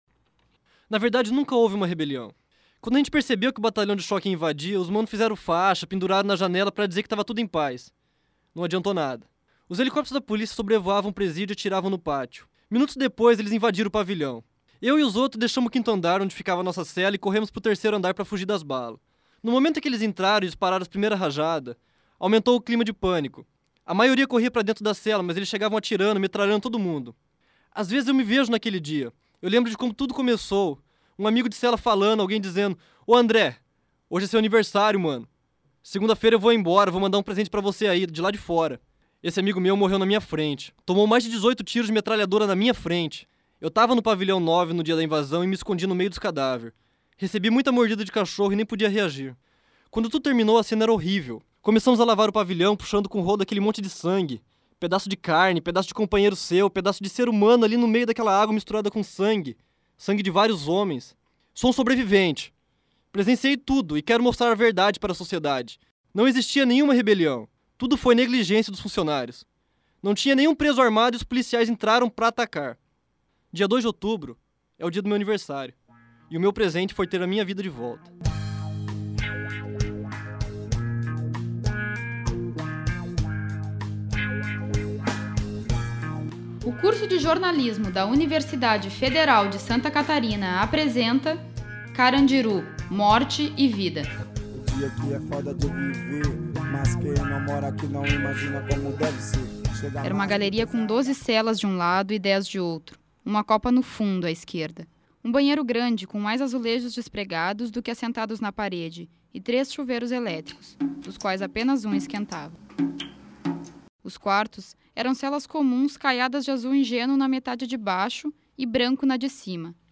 Documentário